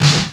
Snare Drum 66-04.wav